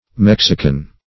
mexican - definition of mexican - synonyms, pronunciation, spelling from Free Dictionary
Mexican \Mex"i*can\, prop. a.